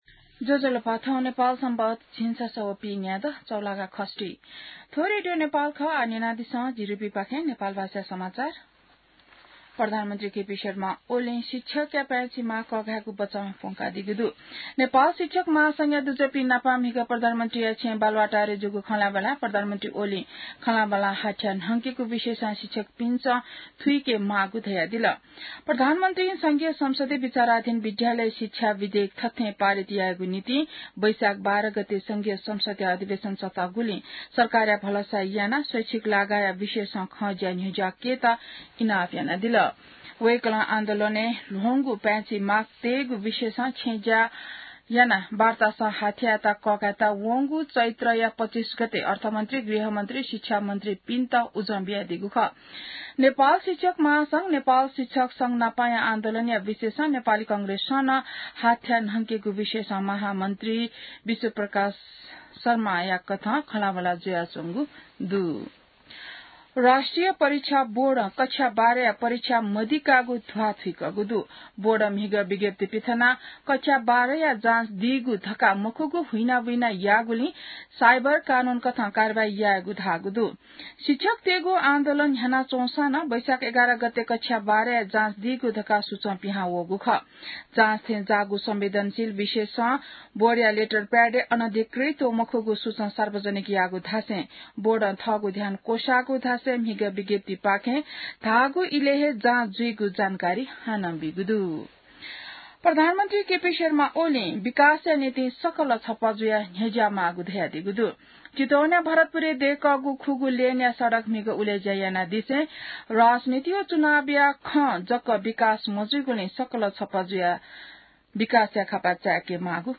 नेपाल भाषामा समाचार : ६ वैशाख , २०८२